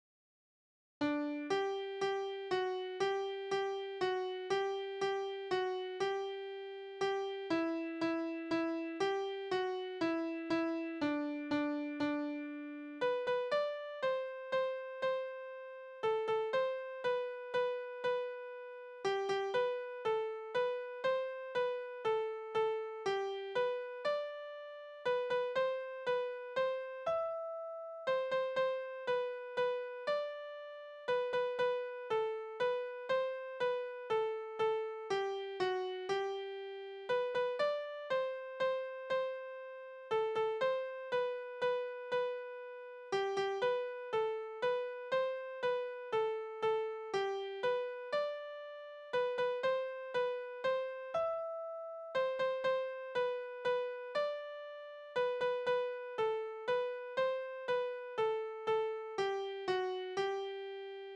Tanzverse: Winkeltanz
Tonart: G-Dur
Taktart: 3/4
Tonumfang: große None
Besetzung: vokal